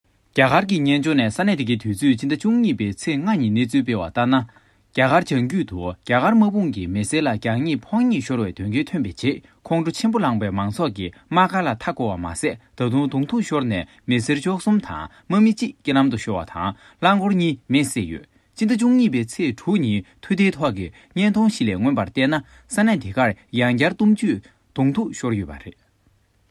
拉萨藏语-电台主播